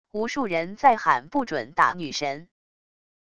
无数人在喊不准打女神……wav音频